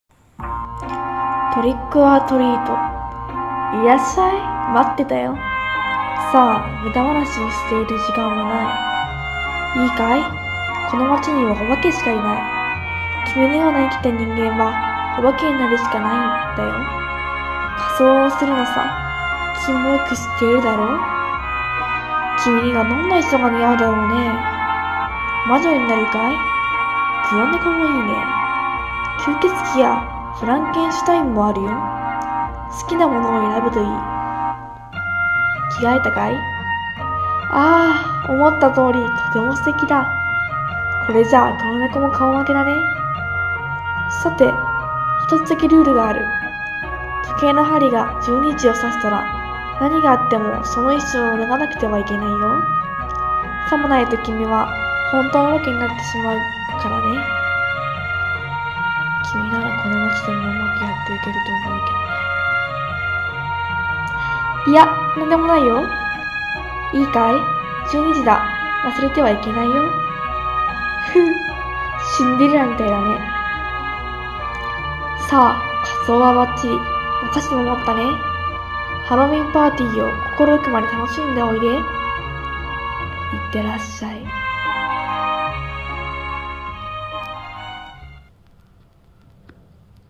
声劇「ようこそハロウィンタウンへ」